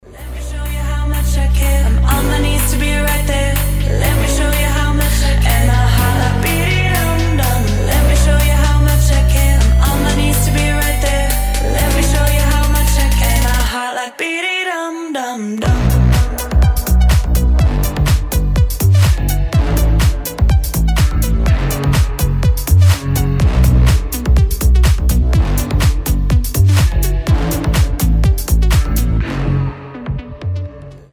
house
slap house , edm